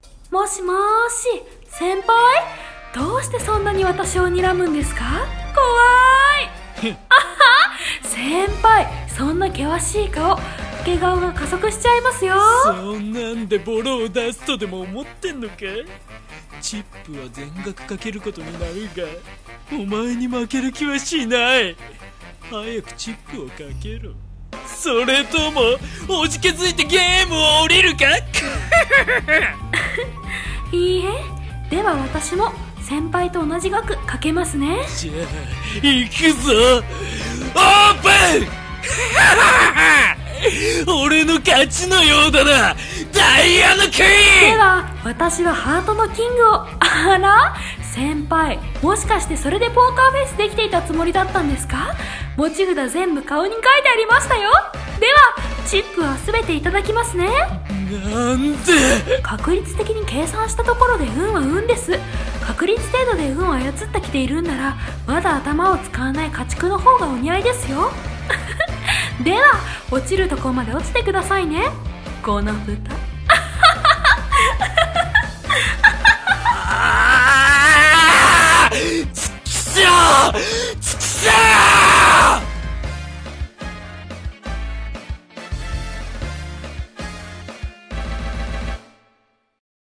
二人声劇